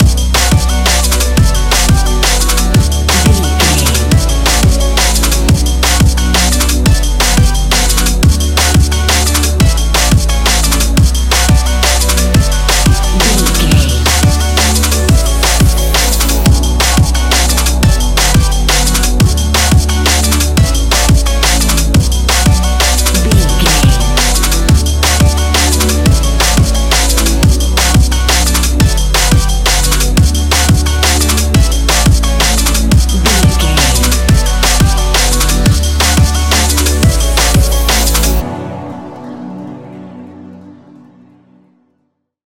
Ionian/Major
D♭
electronic
techno
trance
synths
synthwave
instrumentals